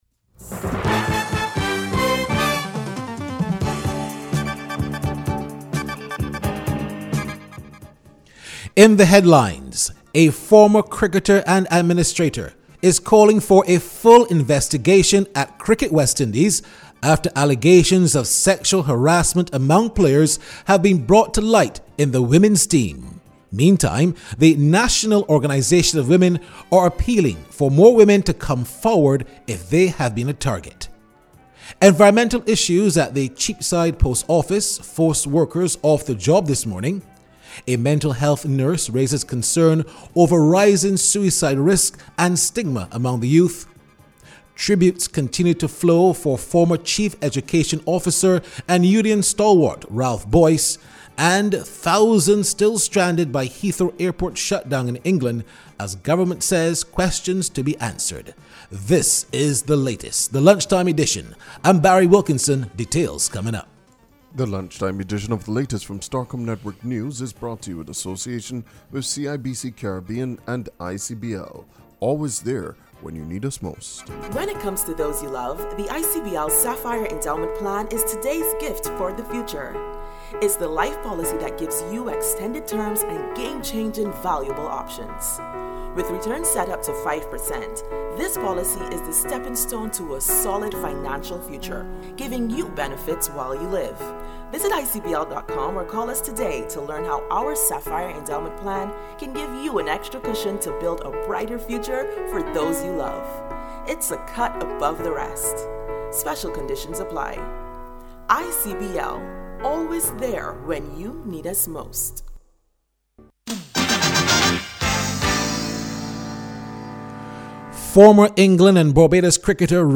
Prime Minister Mia Amor Mottley made the announcement during a news conference at Ilaro Court, disclosing that the new cases are the husband of the female visitor that tested positive earlier this week, a Barbadian man who arrived from the USA at the weekend and a person on a cruise ship.